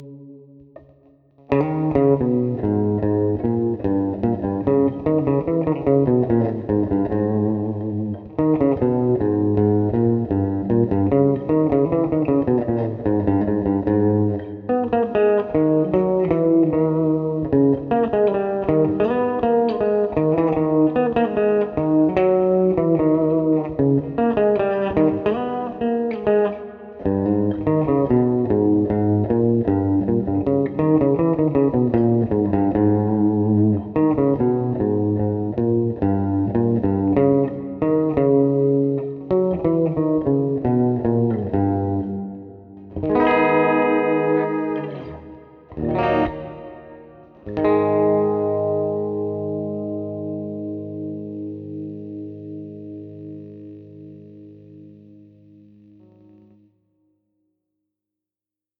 Исправление этих косяков позволило избавится от описанных трудностей и настроить без особых проблем гитару в строй D# стандарт, в котором я обычно играю.
Демки с игрой рифов и всякой херни с разными настройками звука на гитаре. Используются на тоне и громкости потенциометры на 1 МОм.